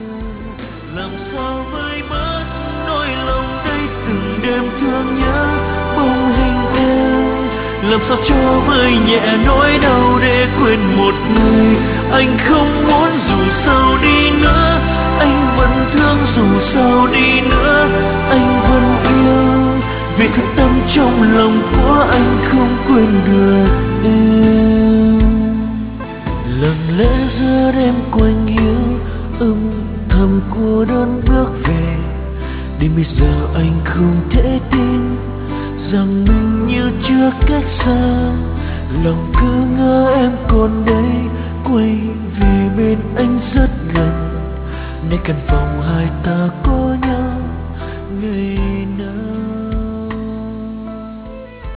chất giọng nam cao, với dòng nhạc pop - ballad